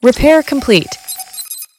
RepairComplete.wav